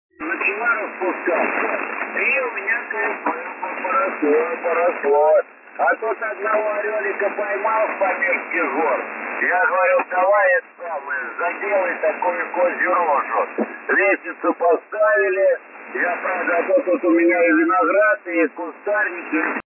Слушал smile фулюганов на частоте 10,460мгц ,занятно,жизнь кипит
Возможно,но при приеме без примочек сложно вытянуть сигнал из эфира,я не стал записывать шумы без обработки,для сравнения нужно наверно было